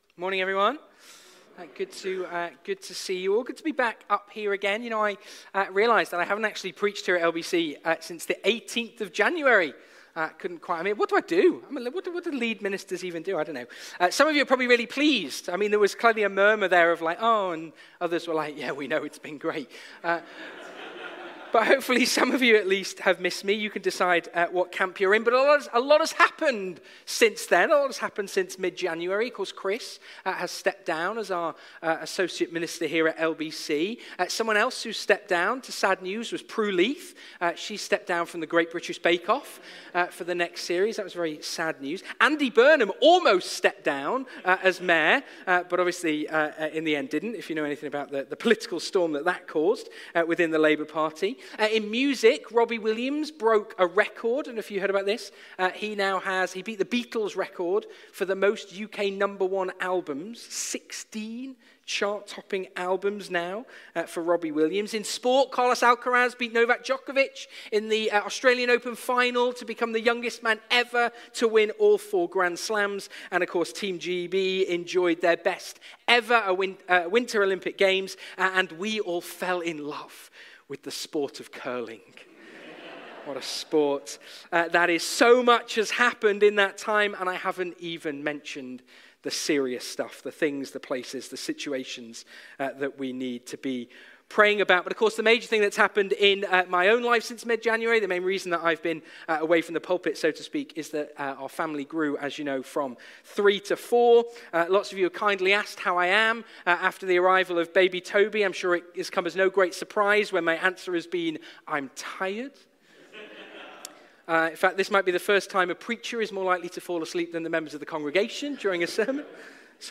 Passage: Luke 23:29-43, 2 Corinthians 1:3-7 Service Type: Sunday Morning